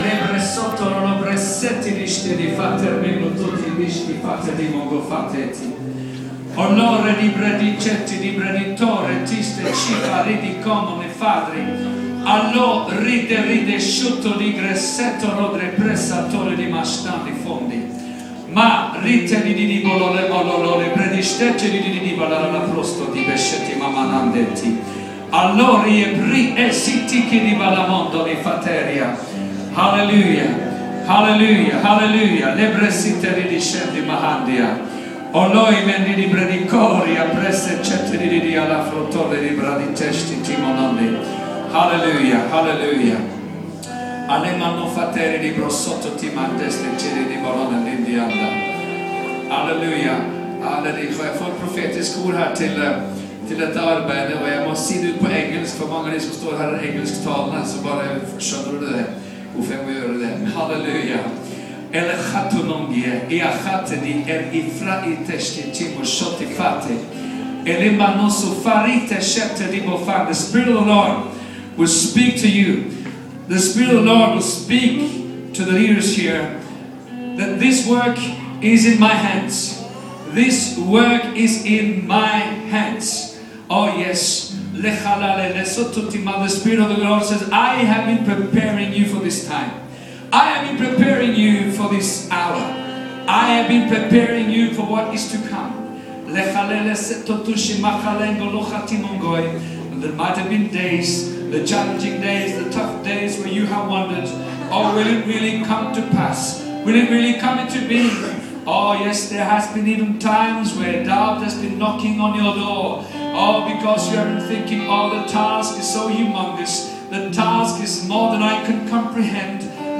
Møte i Jesus Church 2.2.2014.
Møte i ”Kolben” på Kolbotn.